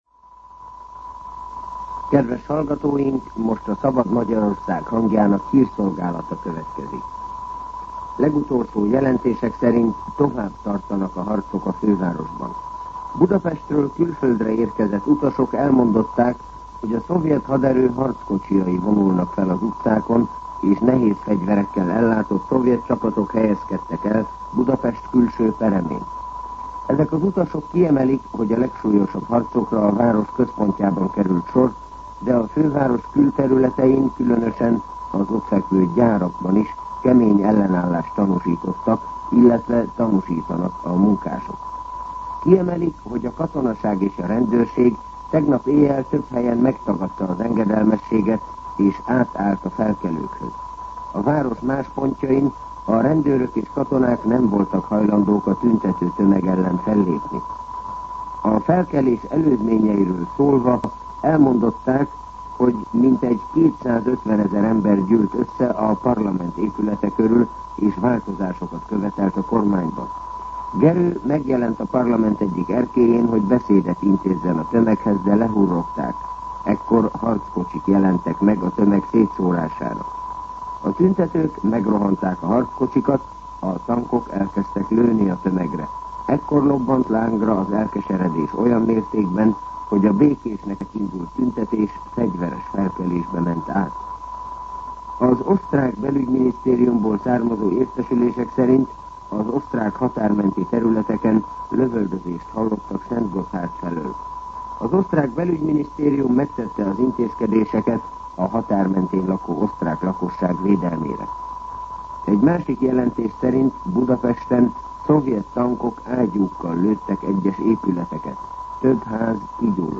Hírszolgálat